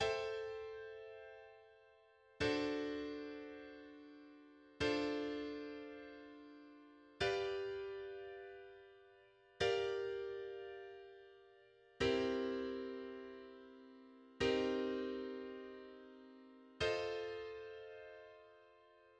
For instance, the B section may appear as follows:[12]